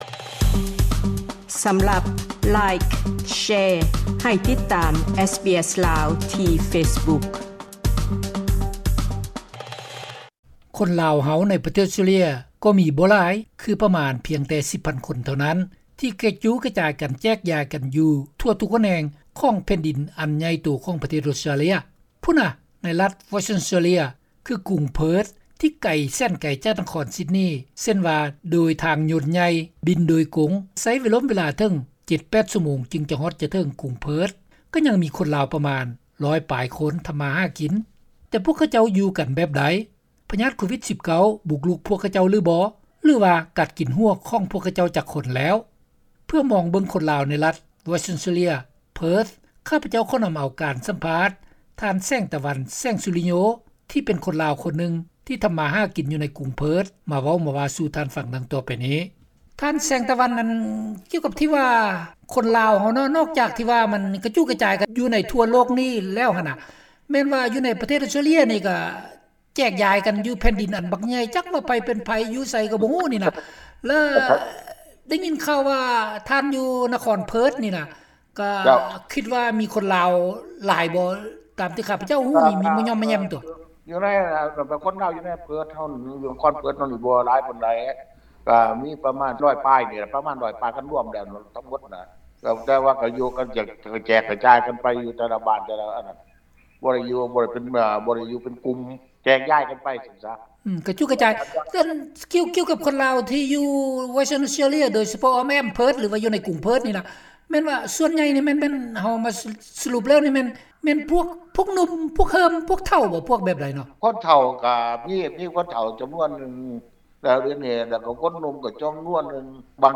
ສຳພາດ: